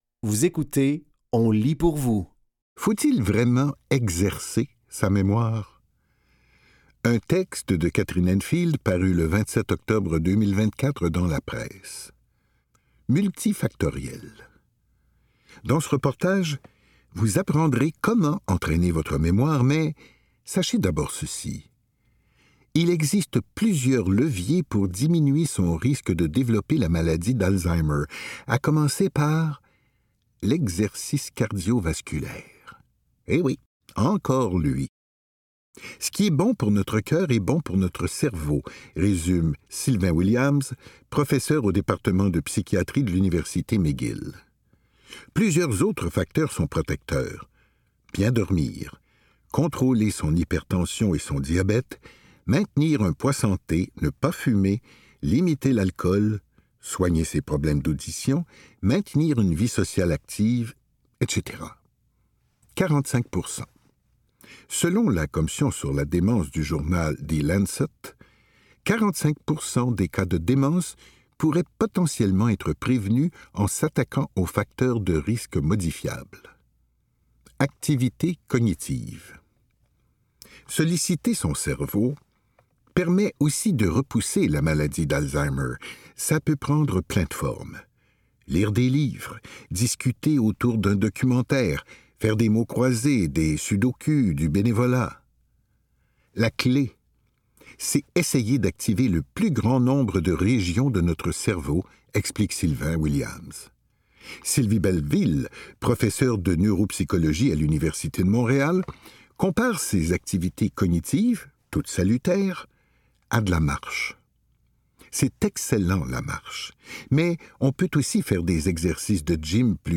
Dans cet épisode de On lit pour vous, nous vous offrons une sélection de textes tirés des médias suivants : La Presse, ICI Ottawa-Gatineau, Le Jounrnal de Montréal, TVA Nouvelles, Radio-Canada et Le Charlevoisien.